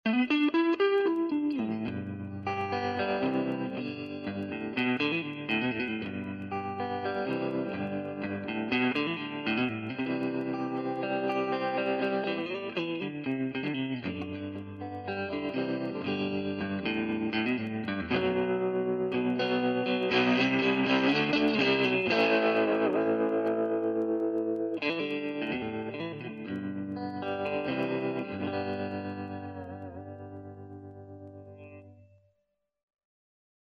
Примеры звучания. попрошу сильно за технику не пинать - очень торопился.
Ничего не обрабатывал. Гитара - шамраевский стратоид, микрофон - Шур 58.